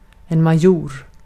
Ääntäminen
Ääntäminen : IPA: [ma.ˈʝoʊɾ] Haettu sana löytyi näillä lähdekielillä: ruotsi Käännös Ääninäyte Substantiivit 1. major US Artikkeli: en .